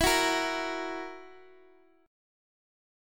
Esus2b5 chord